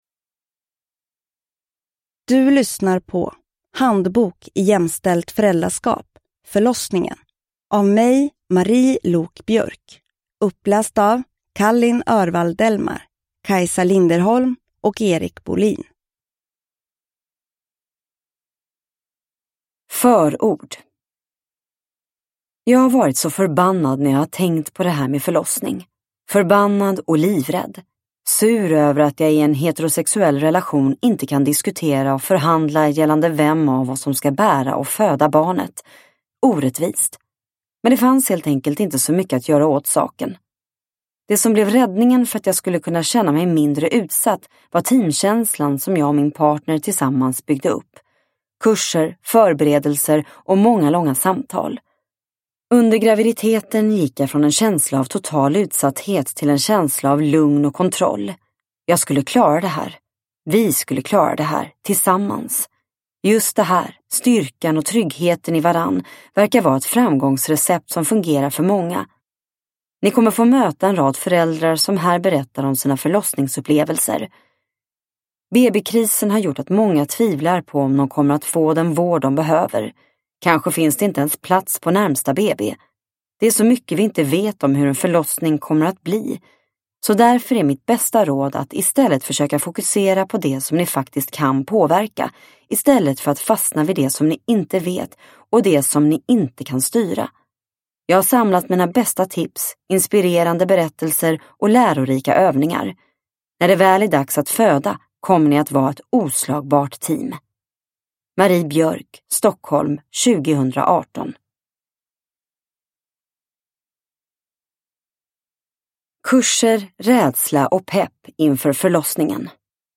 Handbok i jämställt föräldraskap - Förlossningen – Ljudbok – Laddas ner